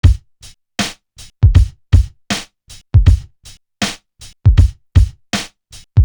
Intrude Drum.wav